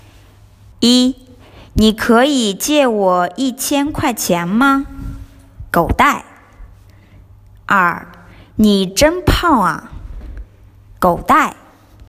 For instance, the word for “go to hell: (gǒu dài)” was “phonetically” translated from English, and it sounds like “go die”.
How to Say “Go Die” in Chinese? 狗 (gǒu dài) = Go to hell/Go die